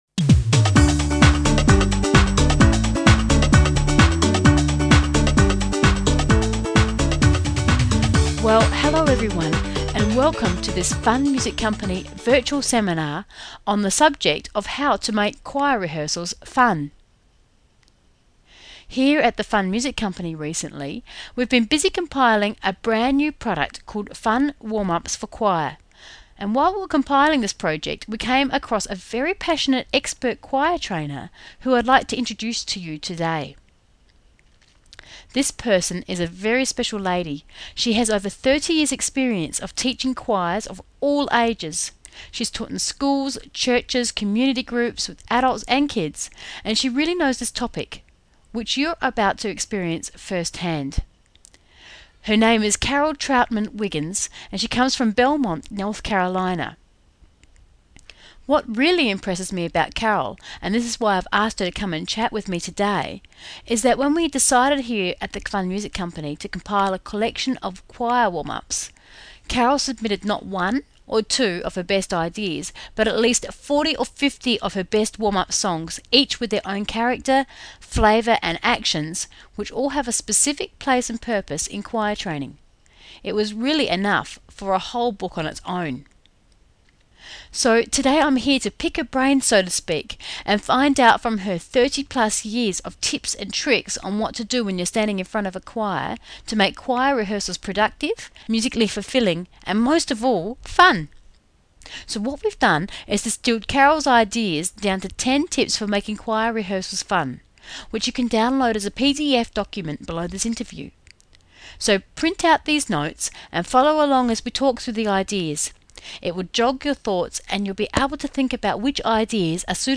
Full interview mp3
choirwarmupsinterview.mp3